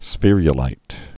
(sfîryə-līt, -ə-līt, sfĕr-)